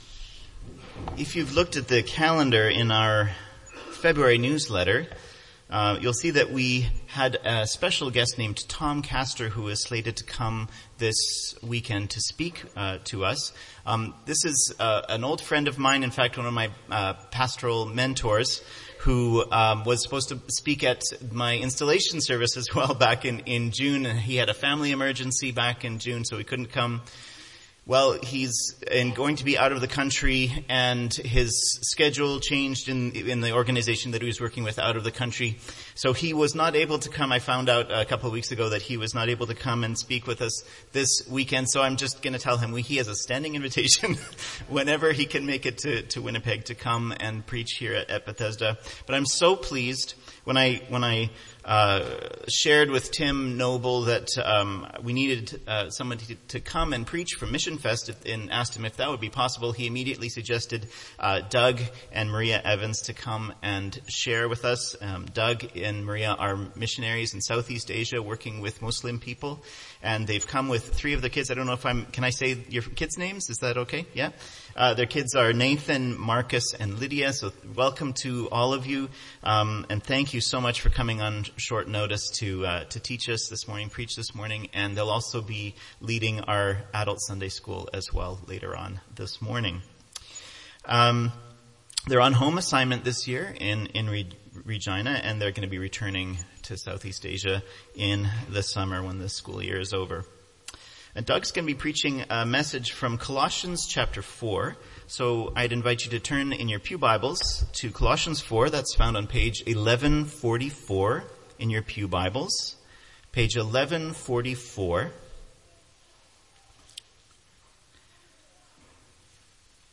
Because of the Hope of the Gospel :Bethesda Sermon Audio
with a reading of Holy Scripture and prayer